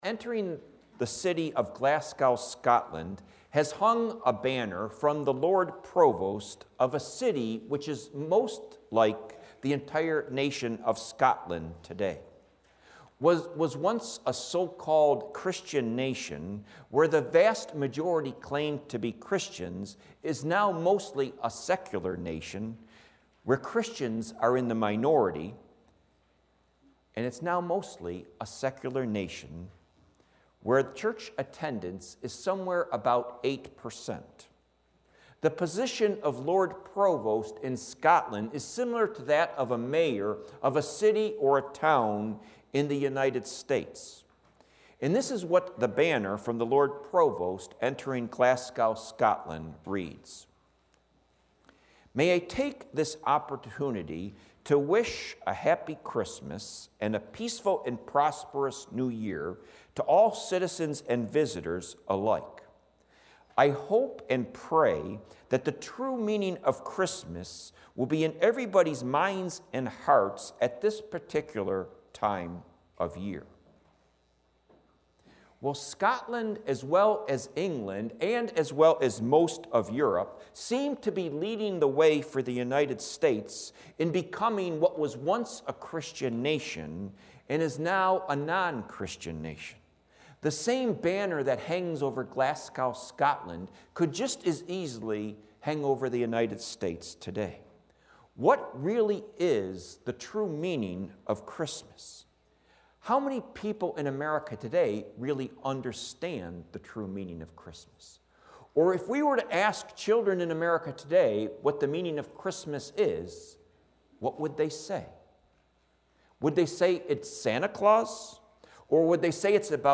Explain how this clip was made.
Service 9:30 am Worship